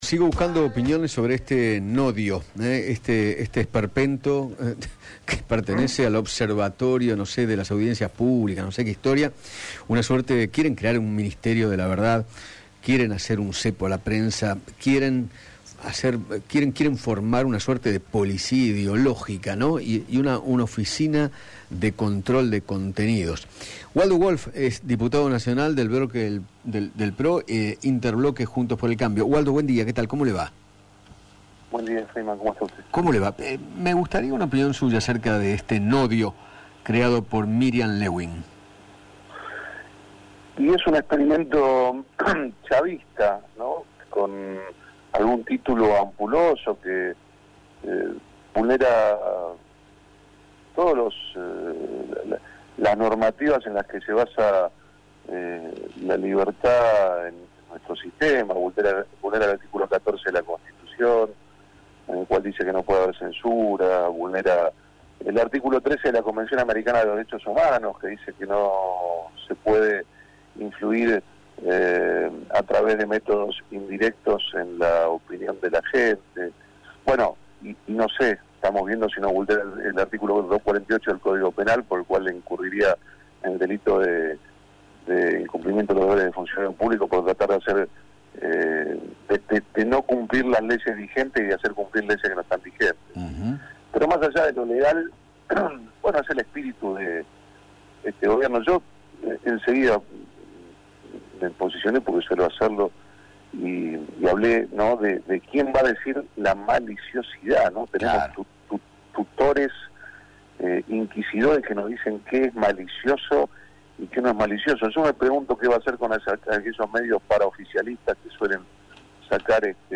Waldo Wolff, diputado nacional, conversó con Eduardo Feinmann acerca de la creación del organismo de vigilancia de los medios de comunicación y manifestó que “Alberto Fernández dijo que Cristina Kirchner era patética, delirante y delincuente ¿El NODIO lo va a sancionar a él?”.